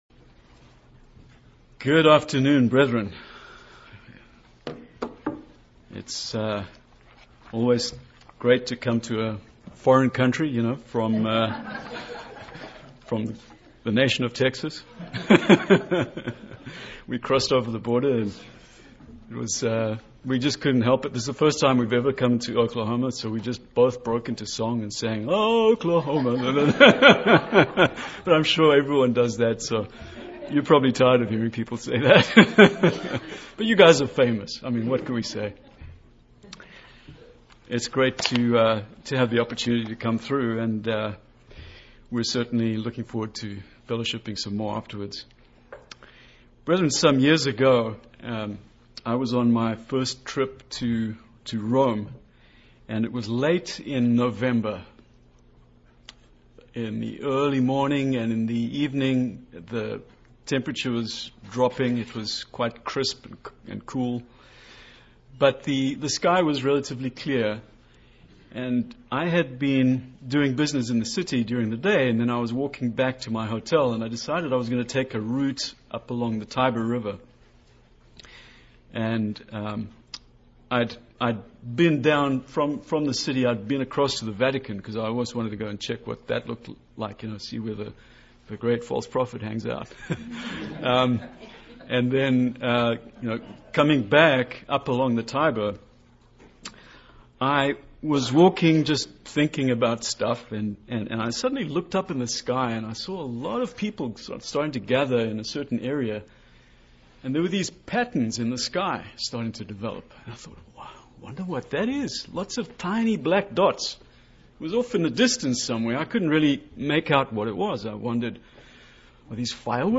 This sermon teaches us what we can learn from starlings.
Given in Lawton, OK